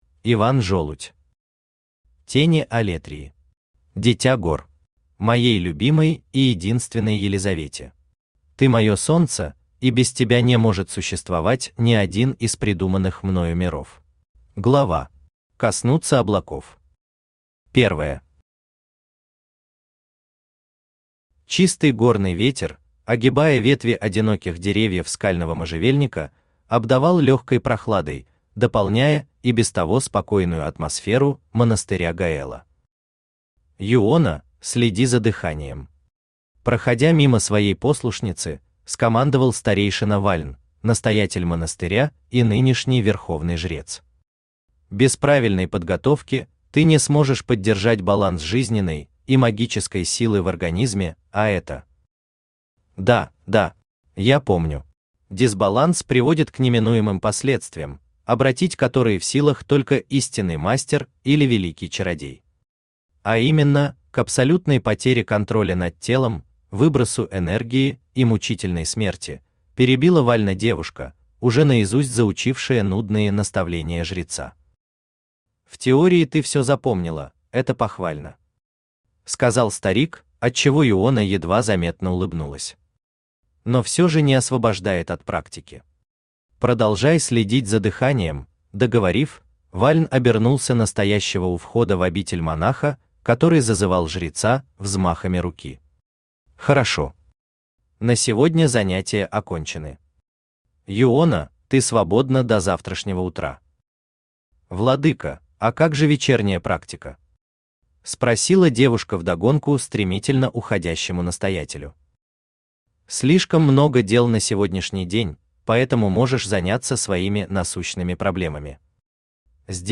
Дитя гор Автор Иван Данилович Жолудь Читает аудиокнигу Авточтец ЛитРес.